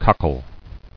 [cock·le]